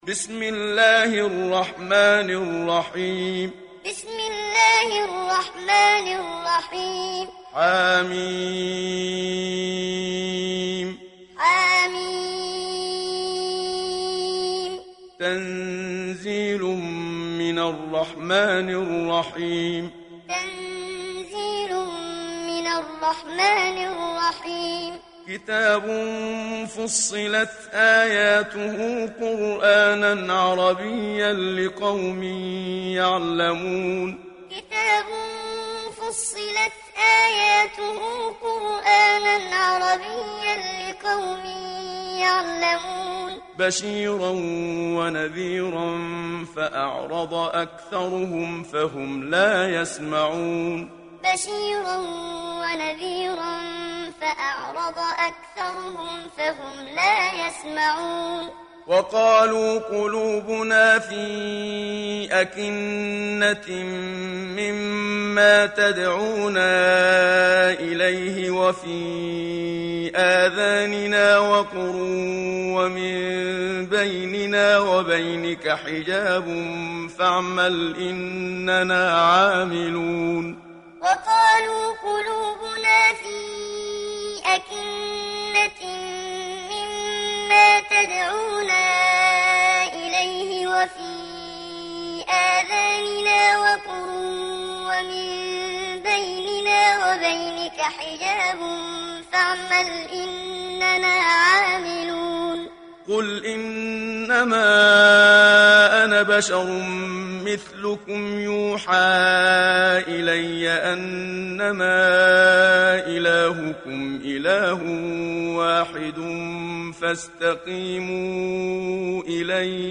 Surah Fussilat Download mp3 Muhammad Siddiq Minshawi Muallim Riwayat Hafs from Asim, Download Quran and listen mp3 full direct links
Download Surah Fussilat Muhammad Siddiq Minshawi Muallim